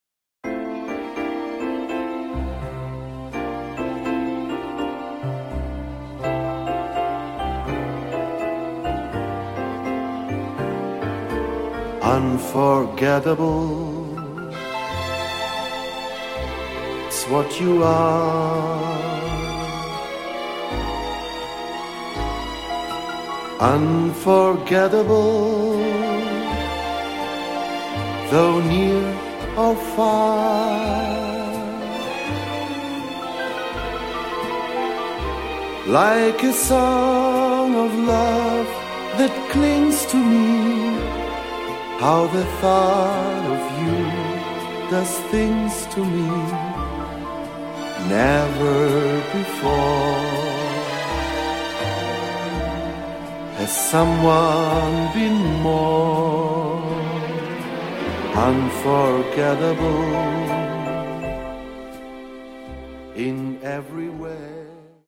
• Pianist / Orgelspieler